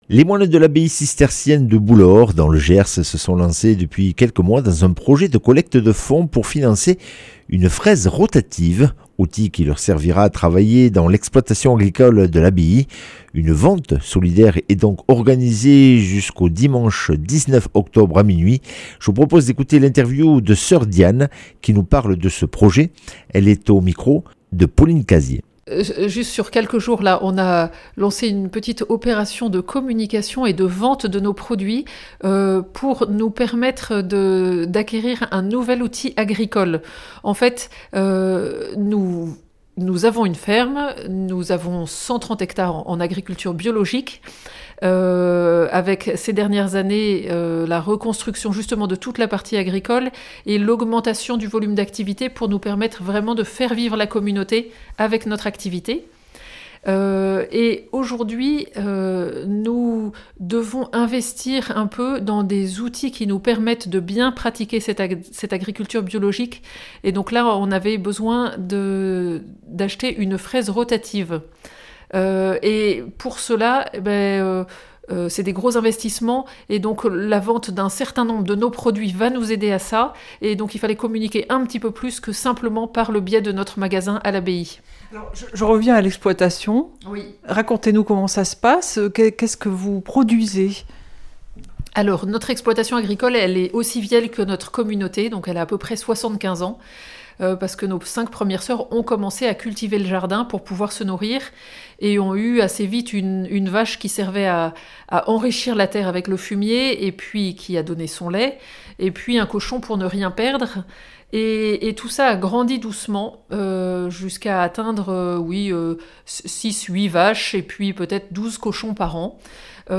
mercredi 15 octobre 2025 Interview et reportage Durée 10 min